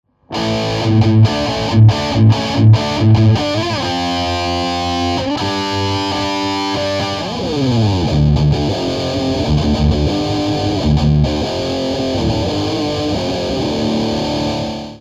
マルチゲイン回路によって、図太いチューブ風ディストーションと限りなきサステインを実現。へヴィメタル/ハードロックサウンドに最適なディストーションです。